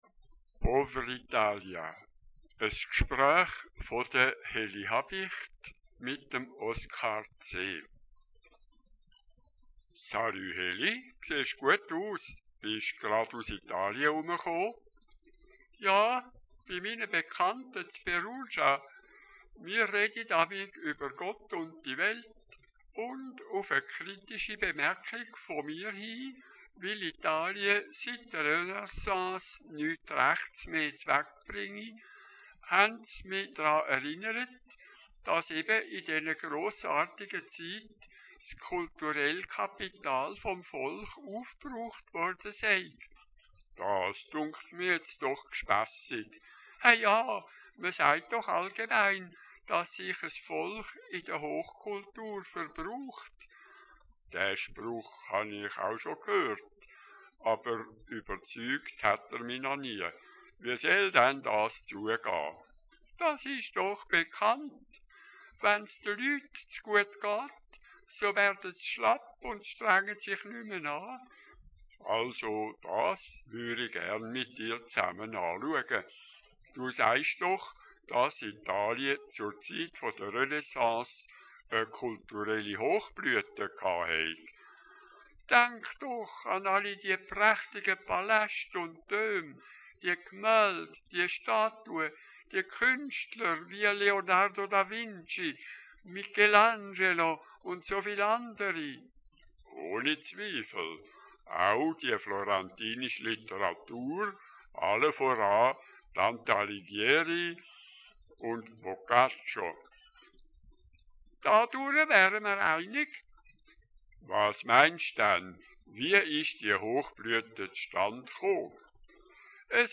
Es Gsprèuhch